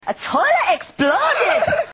Play, download and share TOILET EXPLODED original sound button!!!!
toilet-exploded.mp3